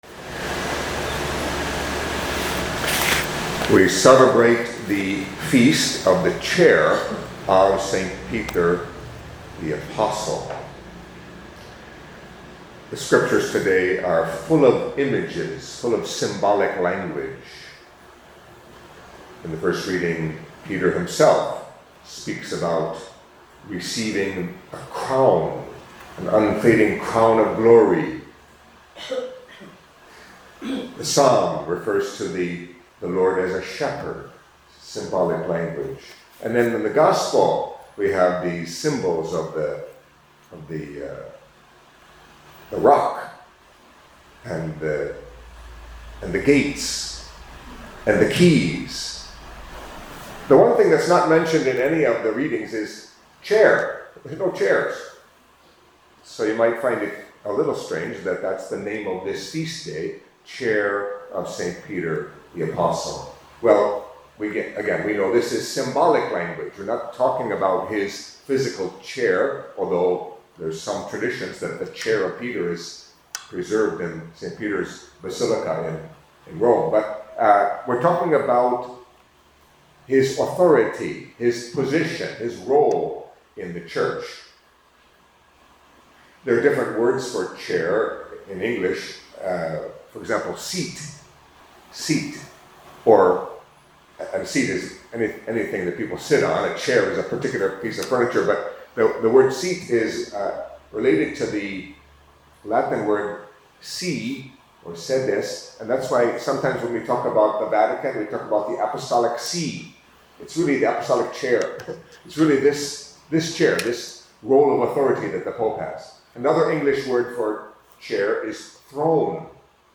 Catholic Mass homily for Feast of the Chair of Saint Peter the Apostle